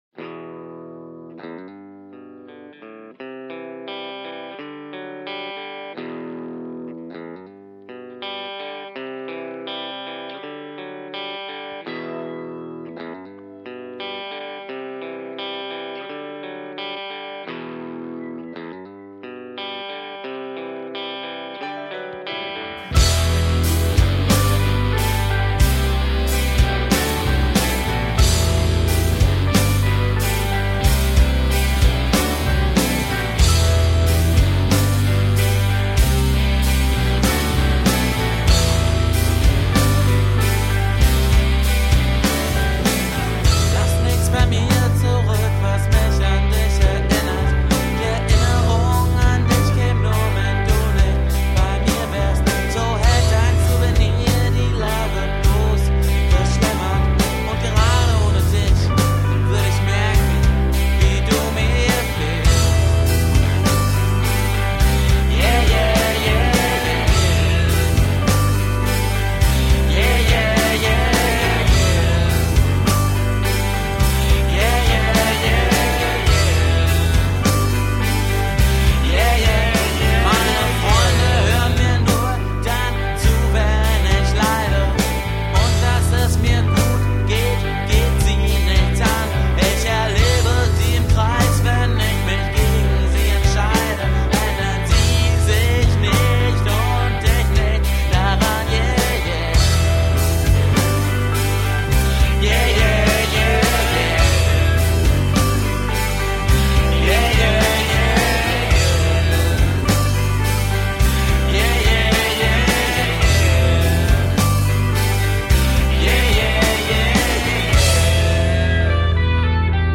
Eine der schönsten Rockplatten aller Zeiten.